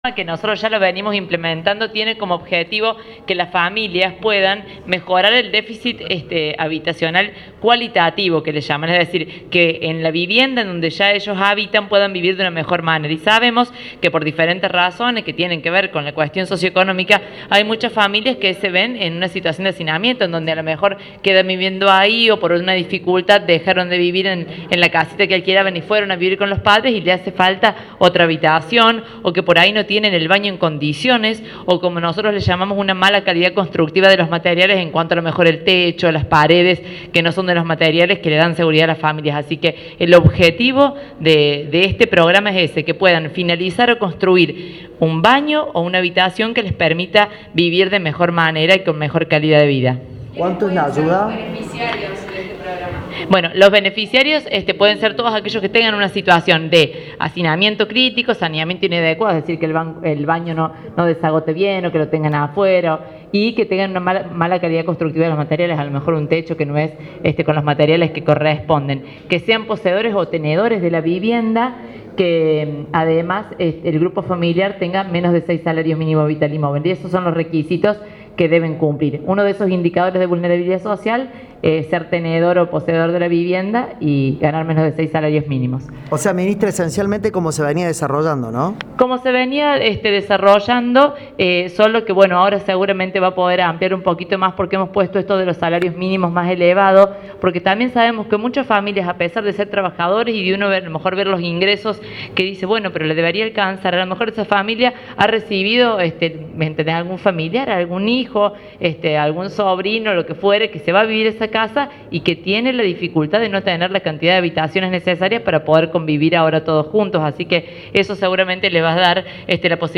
Audio: Laura Jure (Ministra de Promoción del Empleo y de la Economía Familiar).